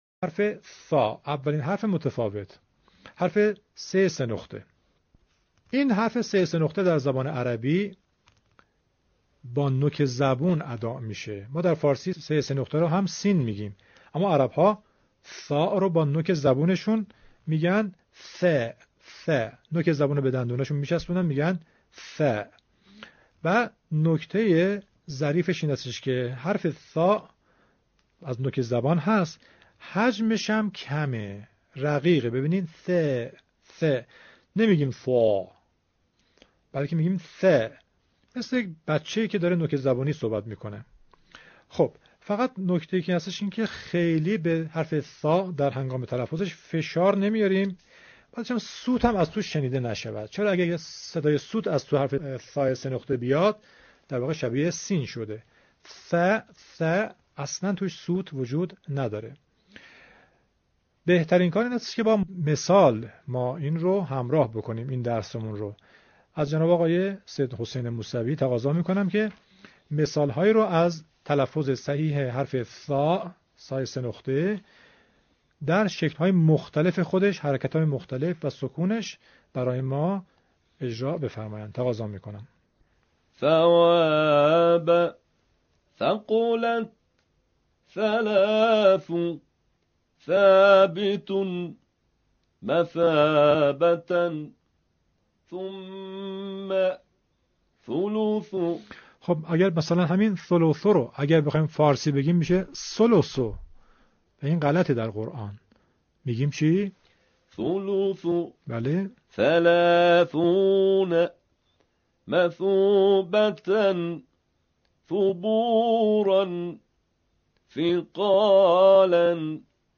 💠تلفظ حرف «ث»💠